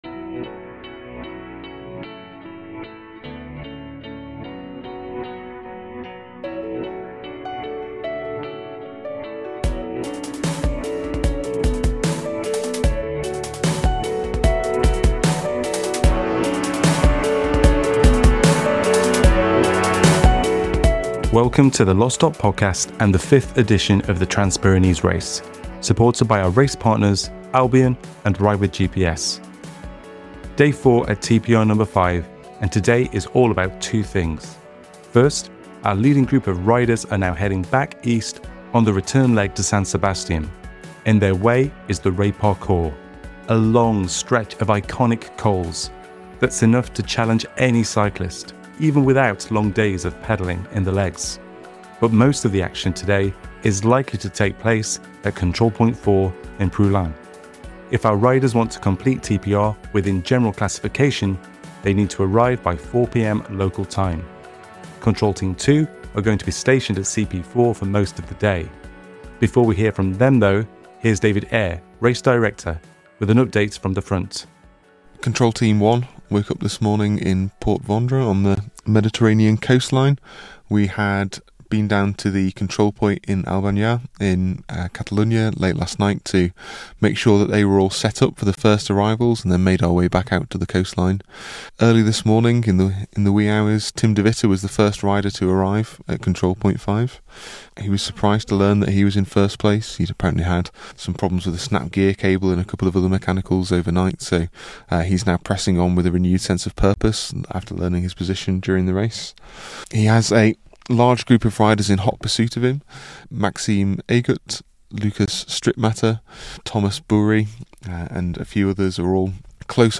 Race Reporters